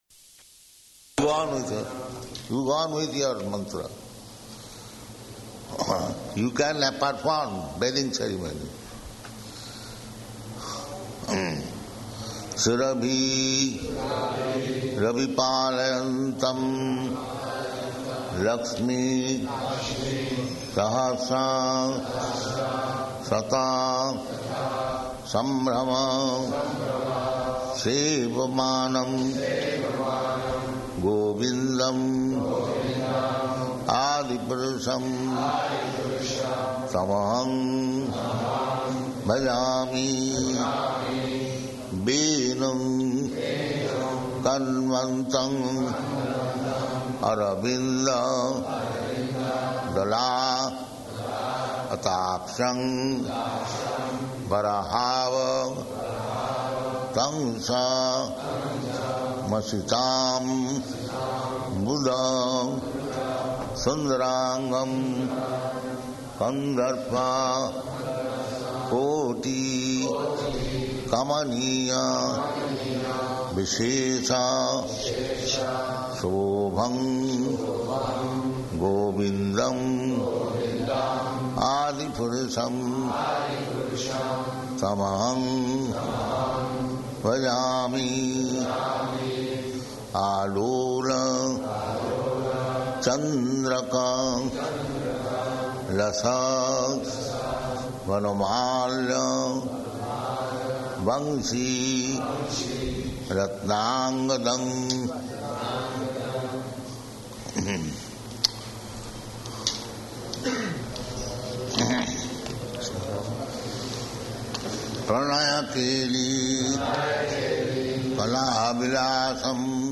Initiations --:-- --:-- Type: Initiation Dated: June 15th 1976 Location: Detroit Audio file: 760615IN.DET.mp3 Prabhupāda: Go on with...